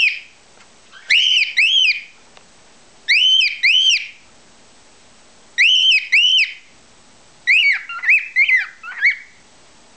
Balzende Hähne
Nymphensittichhähne können sehr unterschiedliche Gesänge entwickeln.
singenderhahn5.wav